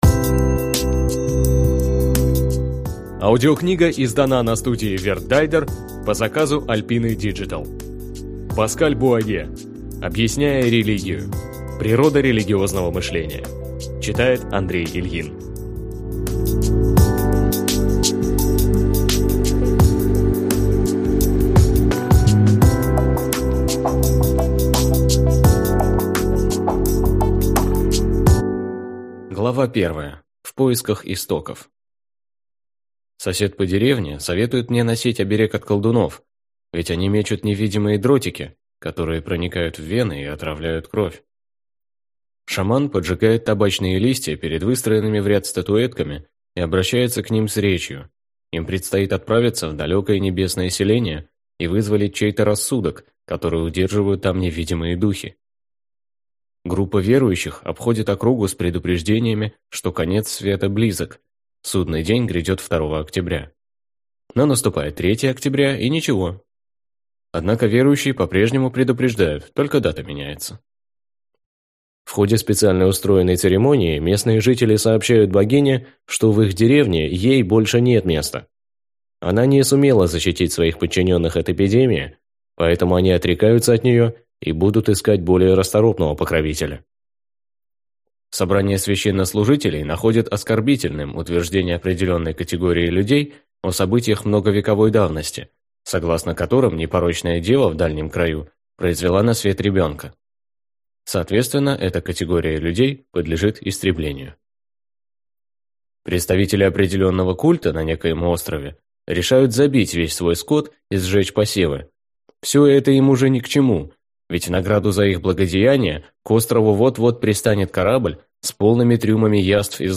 Аудиокнига Объясняя религию. Природа религиозного мышления | Библиотека аудиокниг